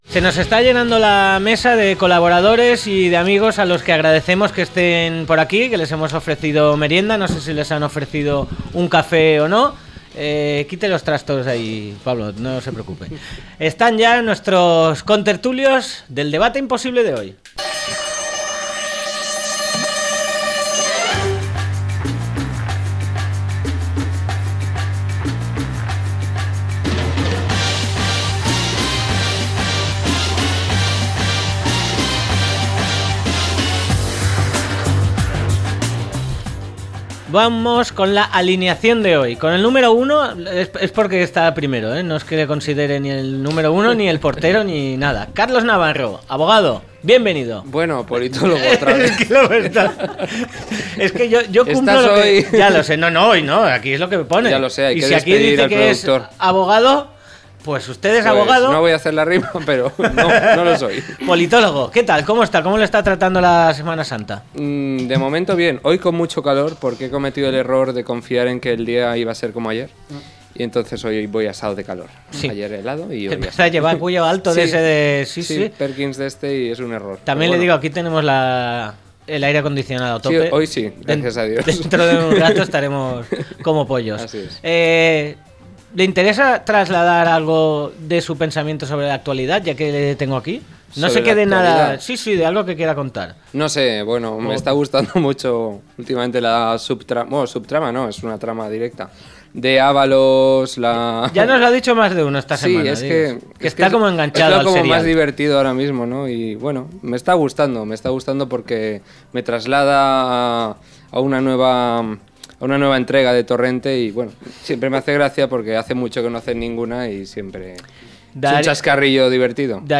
Protocolos inclusivos LGTBI en las empresas, a debate - La tarde con Marina
0416-LTCM-DEBATE.mp3